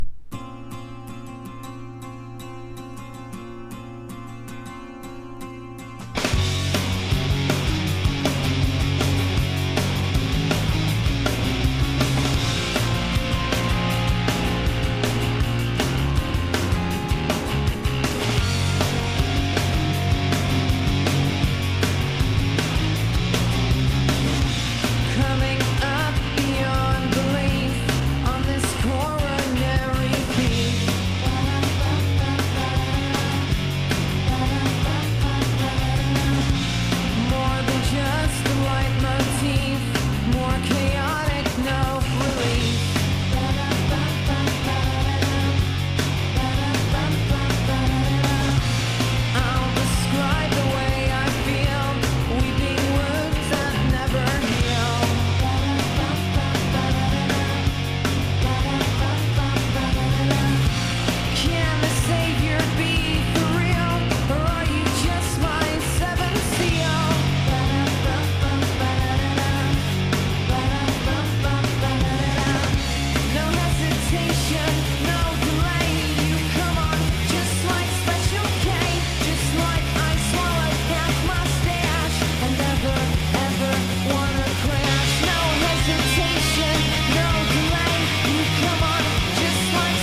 1. 00S ROCK >